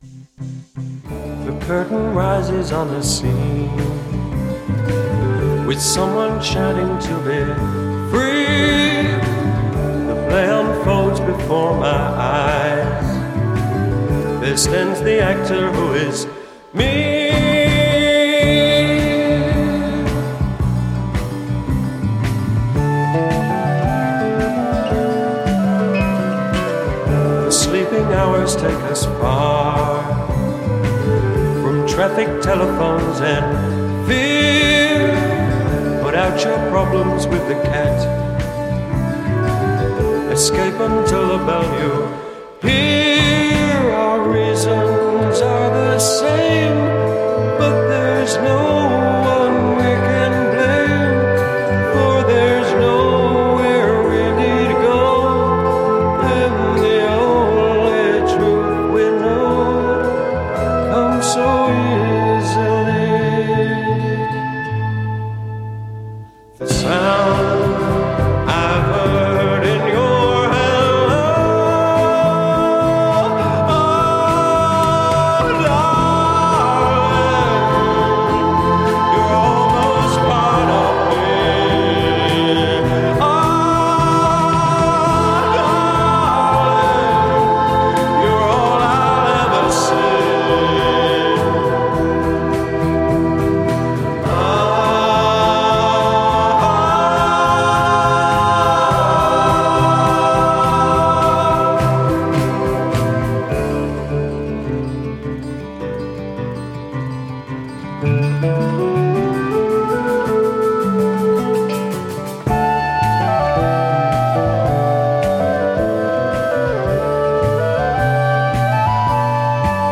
transcendent grandeur
mellotron
flutist